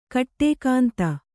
♪ kaṭṭēkānta